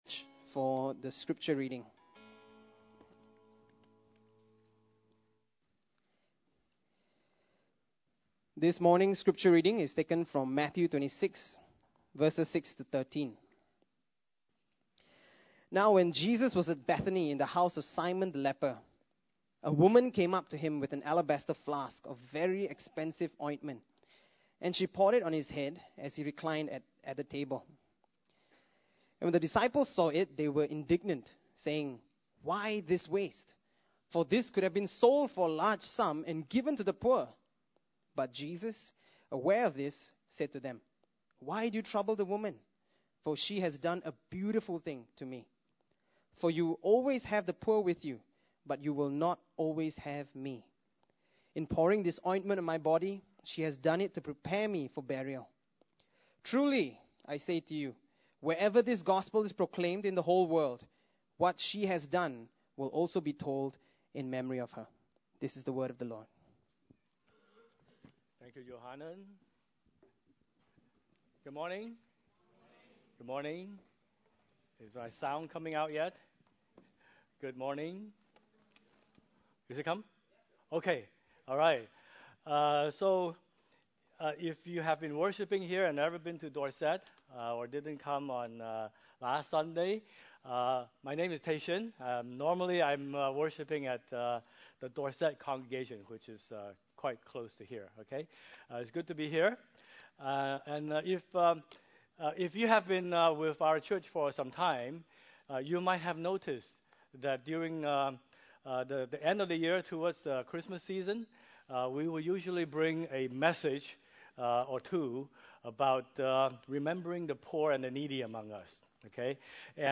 Sermon Summary Extravagant Love_Summary